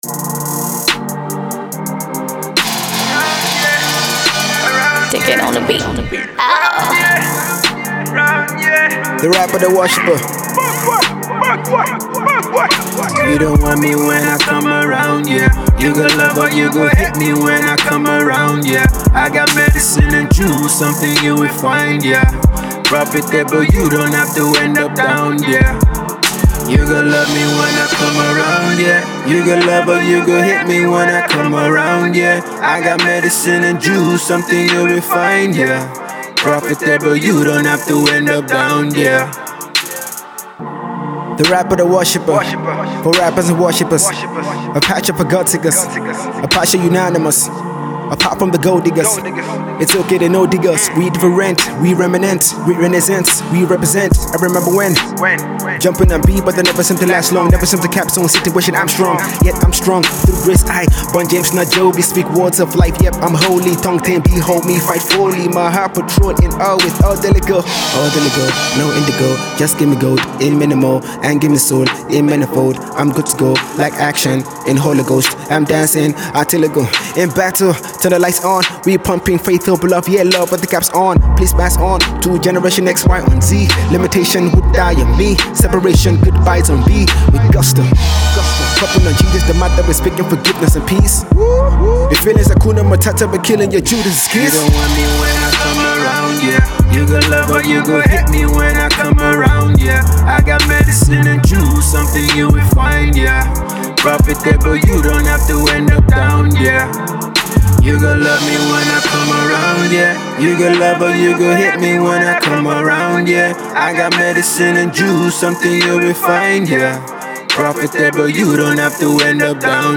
Nigerian Hip Hop